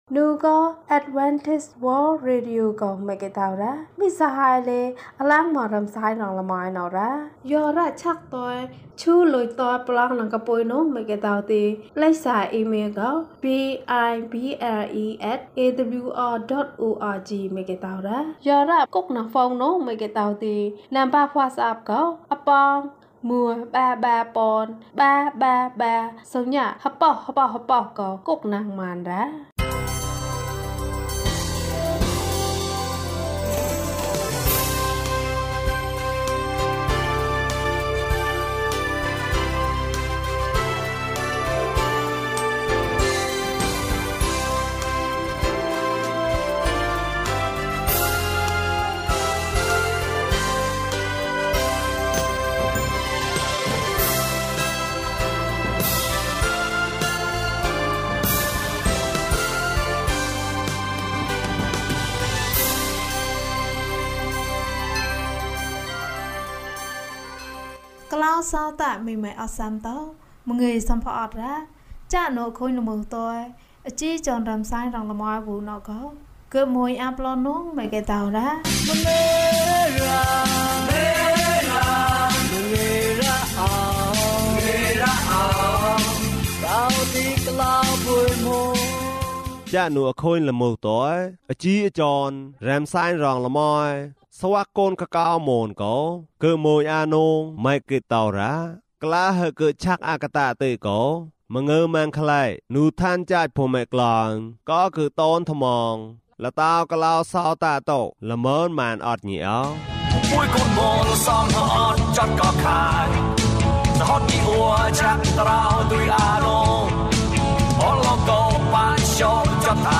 ခရစ်တော်ထံသို့ ခြေလှမ်း။၀၄ ကျန်းမာခြင်းအကြောင်းအရာ။ ဓမ္မသီချင်း။ တရားဒေသနာ။